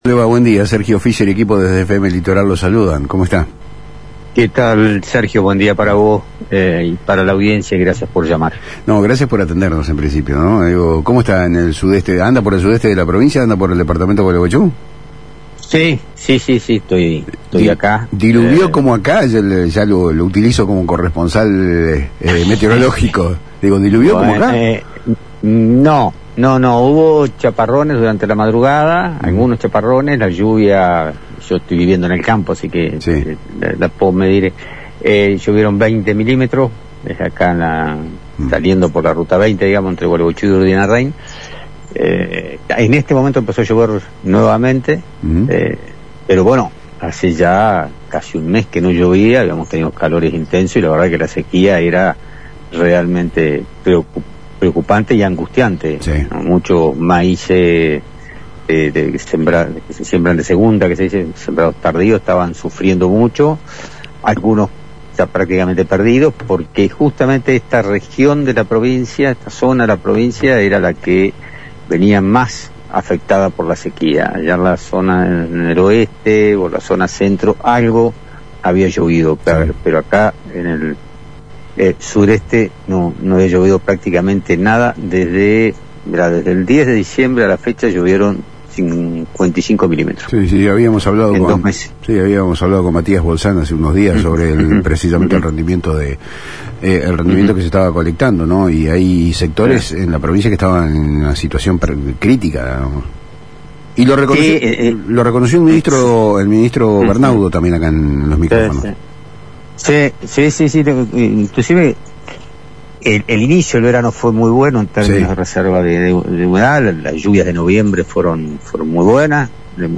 En una extensa entrevista con Palabrs Cruzadas por FM Litoral, el diputado Juan José Bahillo analizó el complejo escenario que atraviesa la producción entrerriana.